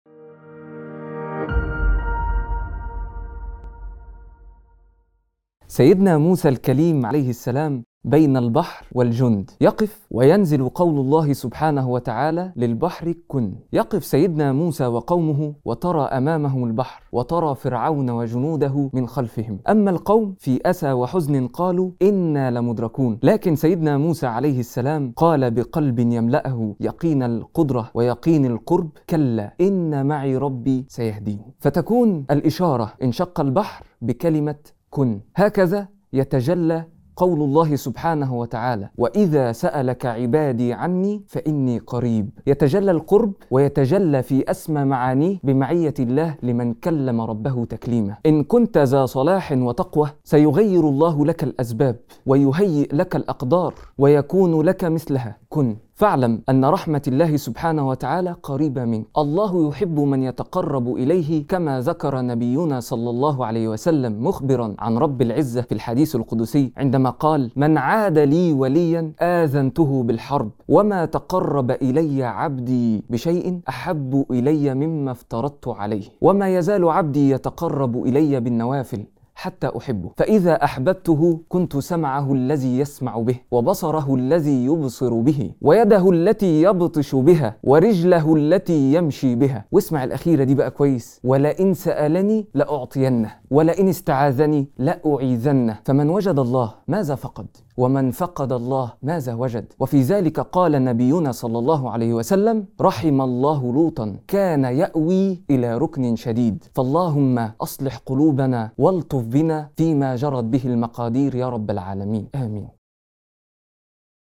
موعظة مؤثرة تستعرض قصة سيدنا موسى عليه السلام مع فرعون لتوضح معنى معية الله تعالى لعباده المؤمنين. يسلط المحتوى الضوء على أهمية التقرب إلى الله بالنوافل وكيف أن القرب منه سبحانه هو أعظم ملجأ وأقوى سبب لتغيير الأقدار.